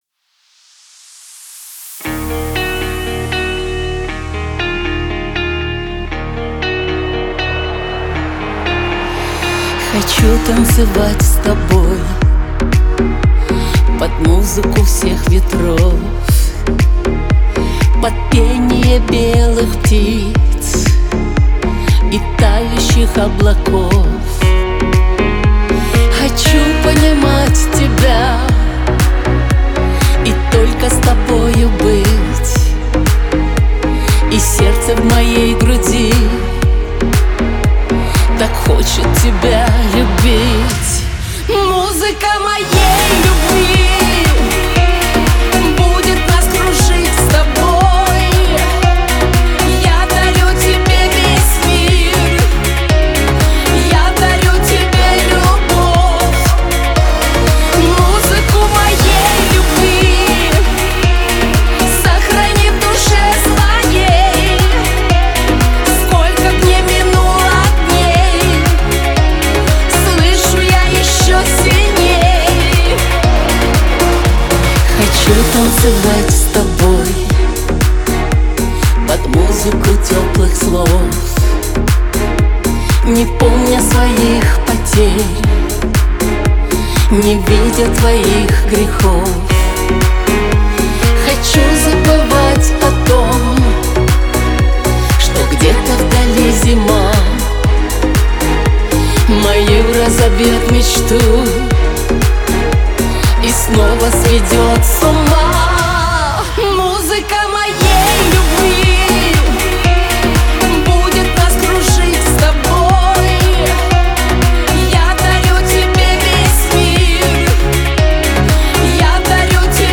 это романтичная поп-баллада